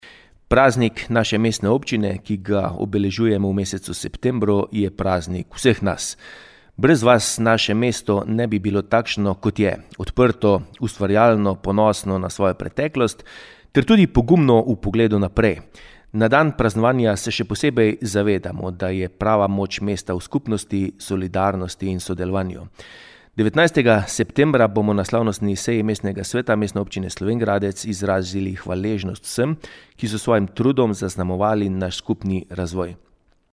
Župan Tilen Klugler pa je ponosen prav na vse občane:
IZJAVA KLUGLER 1.mp3